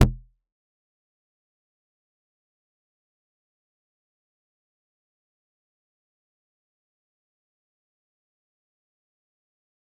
G_Kalimba-A0-f.wav